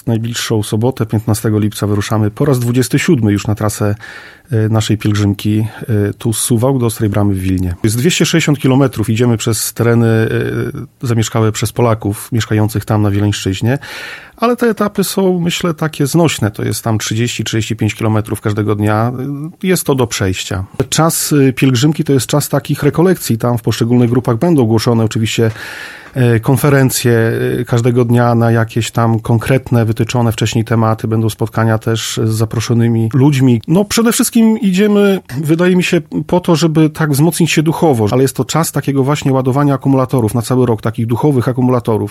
W ciągu 12 dni przejdą około 260 kilometrów. O szczegółach opowiadał we wtorek (11.07) w Radiu 5